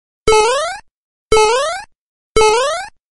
Mario Jump Sound Effect: Unblocked Meme Soundboard
Play the iconic Mario Jump Sound Effect for your meme soundboard!